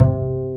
Index of /90_sSampleCDs/Roland - String Master Series/STR_Cb Pizzicato/STR_Cb Pizz 2